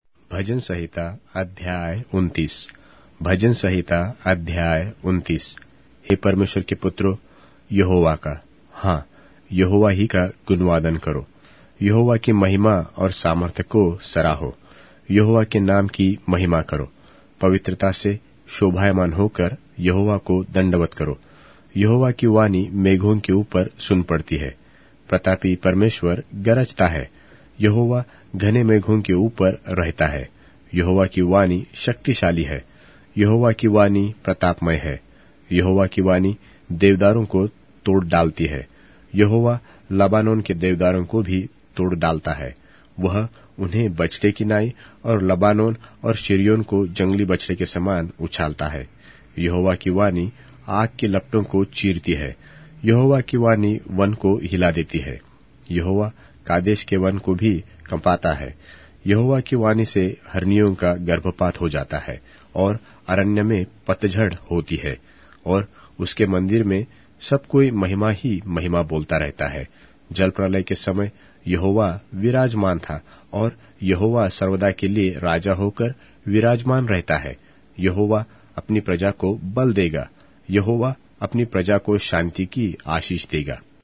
Hindi Audio Bible - Psalms 7 in Rcta bible version